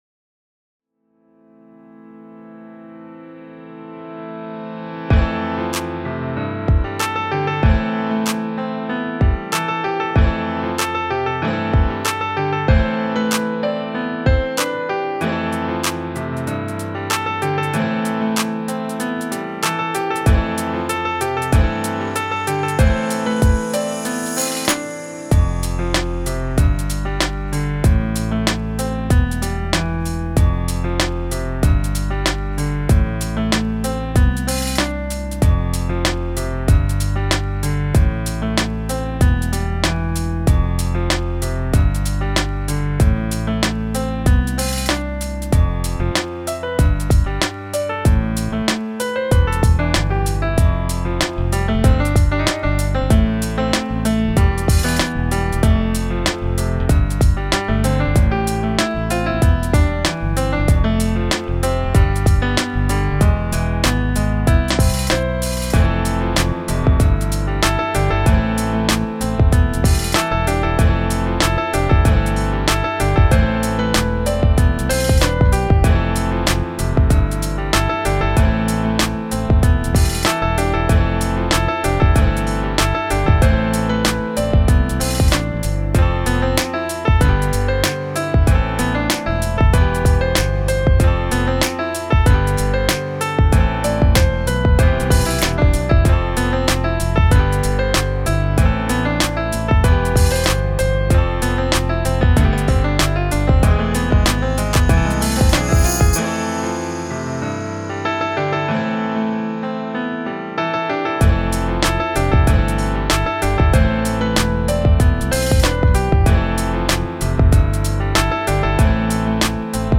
儚い雰囲気のピアノビートを久しぶりに作りたくなって作った楽曲。